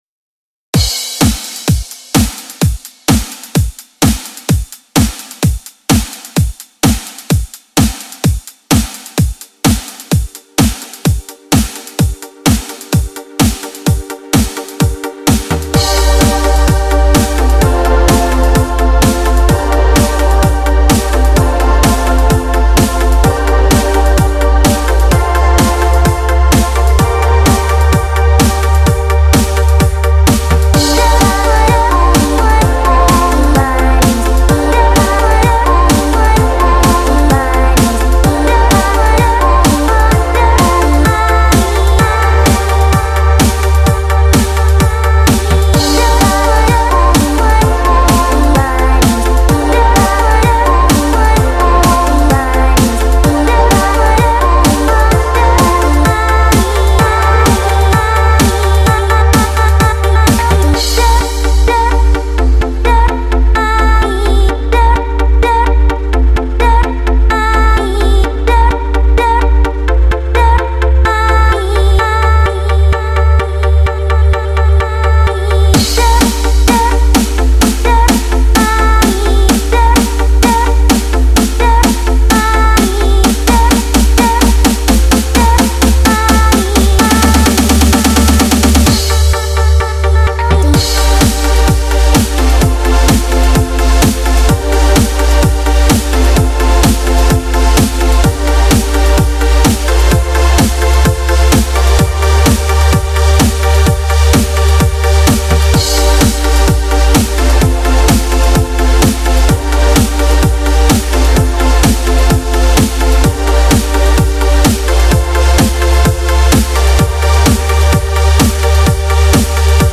5:52 Genre: Progressive/Trance Cover Art